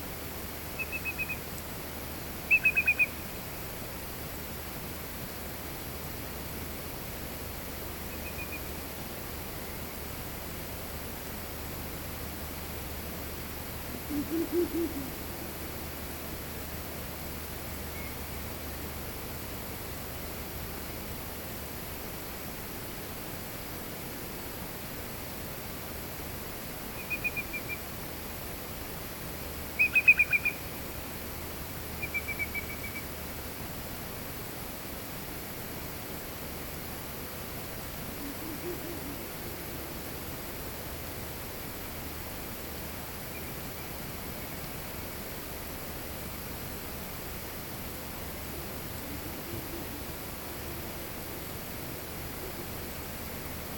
средний кроншнеп, Numenius phaeopus
Примечания/Vismaz 2. Vairākās vietās dzirdēta migrācija.